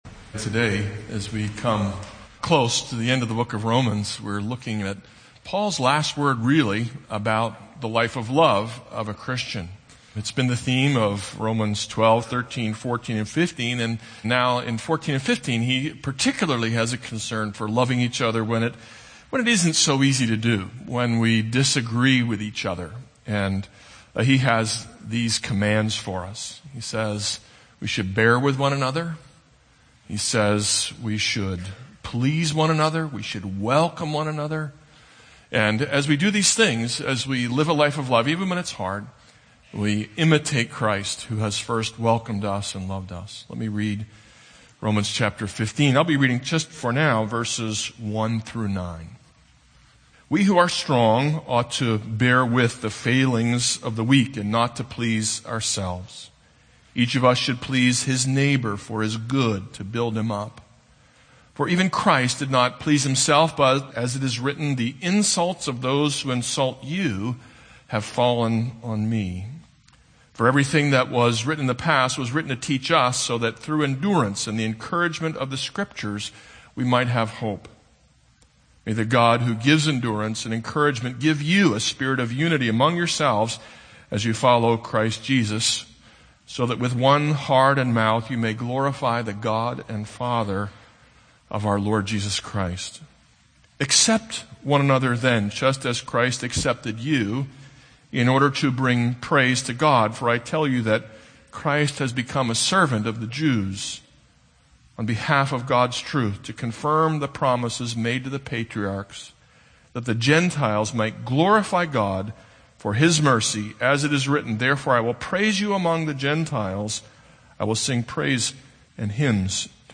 This is a sermon on Romans 15:1-13.